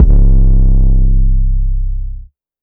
2. CAROUSEL 808.wav